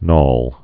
(nôl)